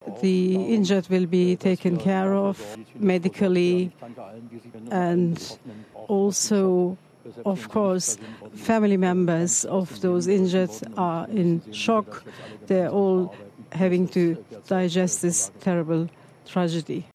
Speaking through an interpreter, the state premier of Saxony-Anhalt, Reiner Hasleoff, says dozens of people have been taken to hospital.